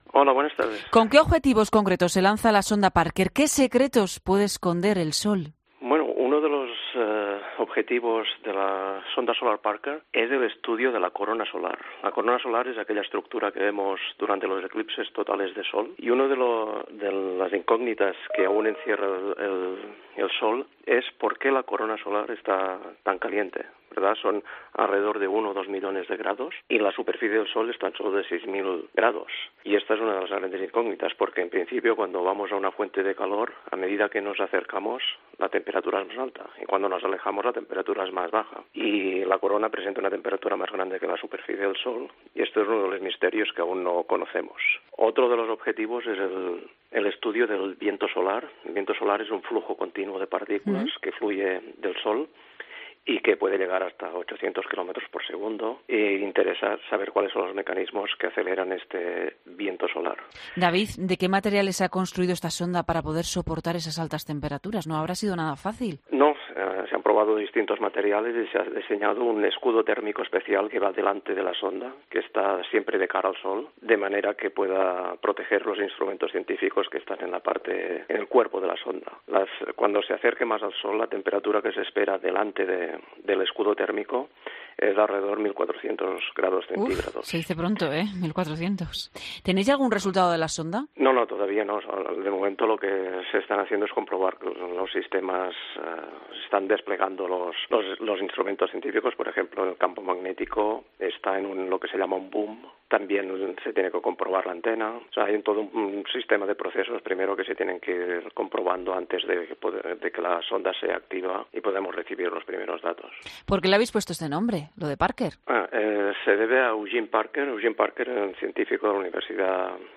Entrevistas en Mediodía COPE